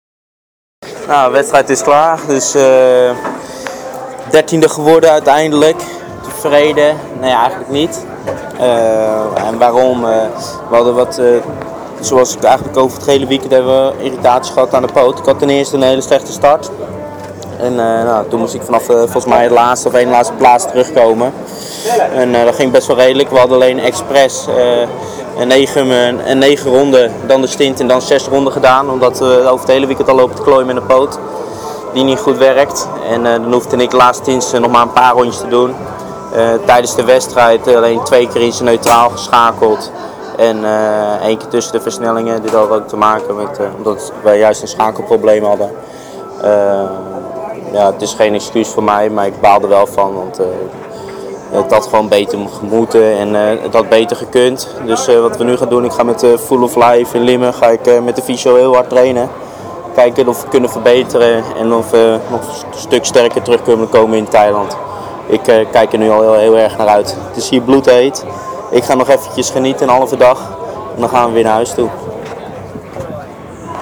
Na afloop van de race zochten we de twee Nederlanders op en vroegen hun naar een eerste reactie.